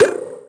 VES2 Percussive 097.wav